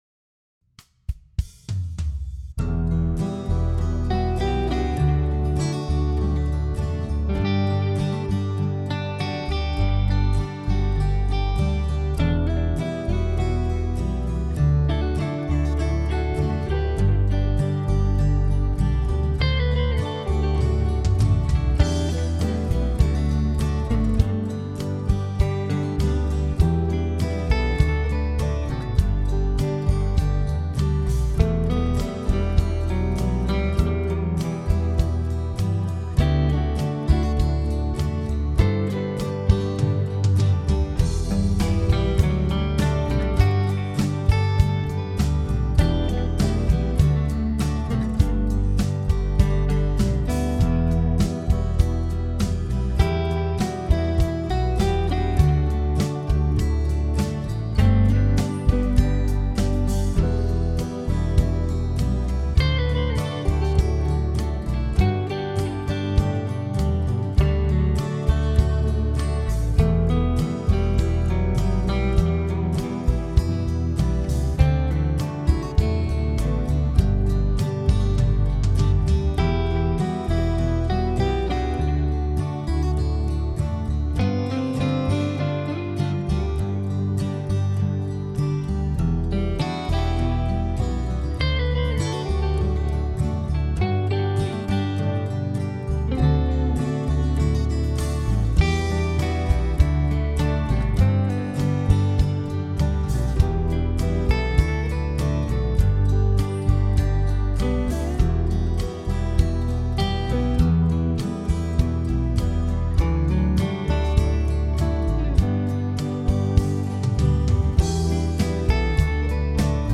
Home > Music > Rock > Bright > Smooth > Medium